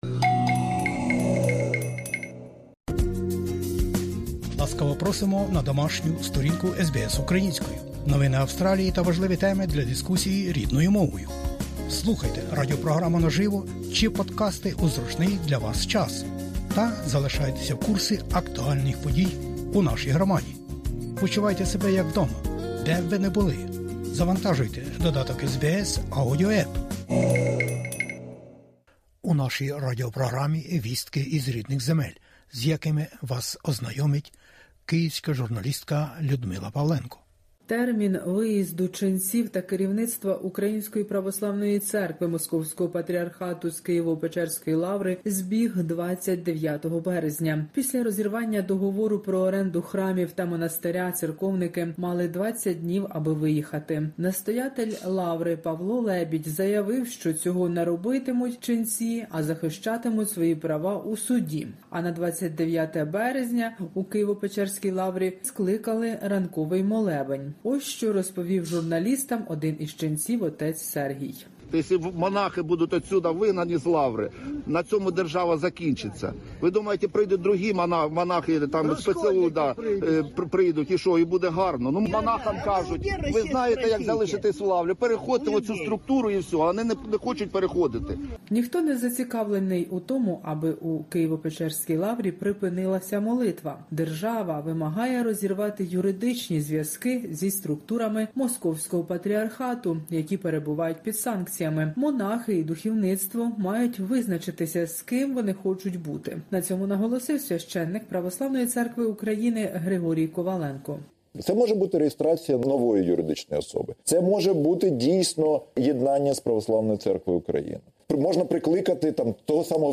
Добірка новин із героїчної України.